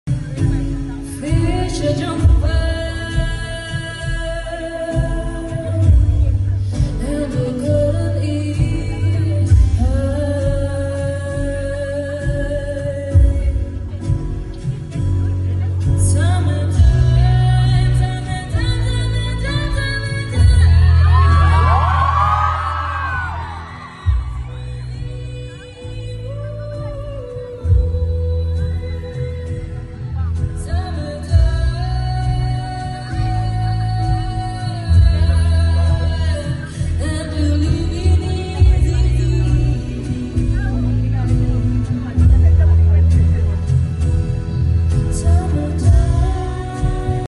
en Madrid